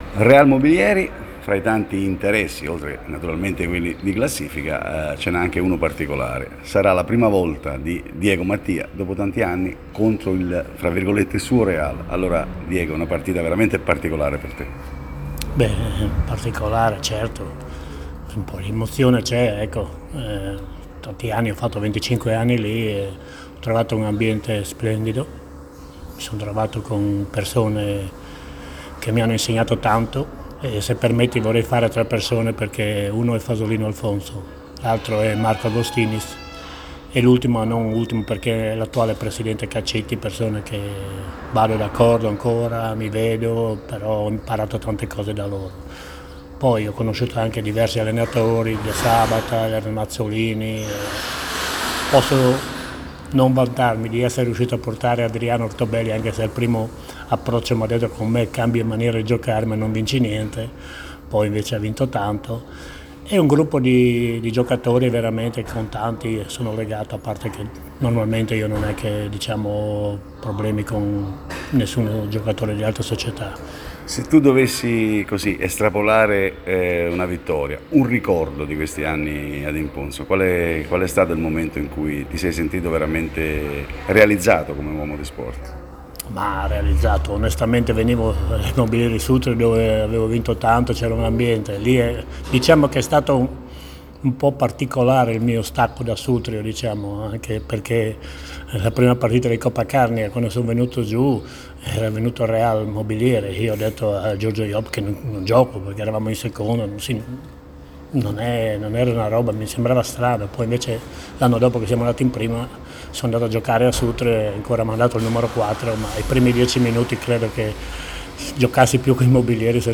Lo abbiamo avvicinato per farci raccontare come vive questa particolare vigilia.